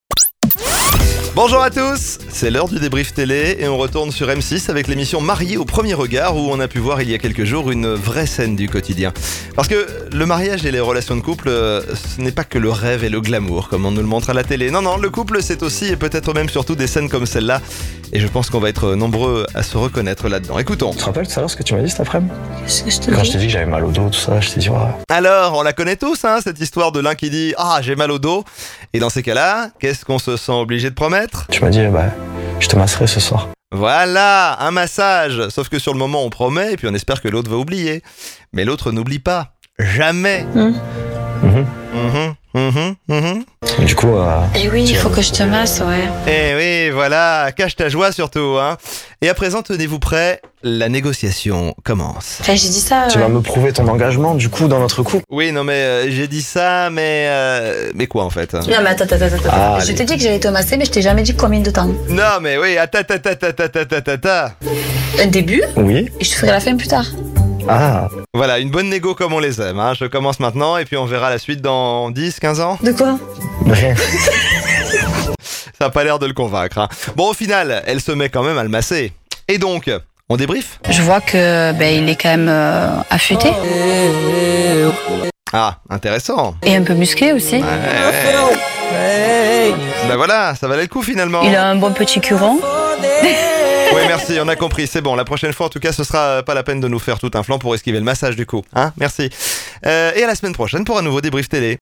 MAXI L’AIR propose une chronique riche en contenus pour revenir sur les meilleurs moments de la télévision : zappings, moments amusants, dramas, extraits … le tout dans une écriture amusante.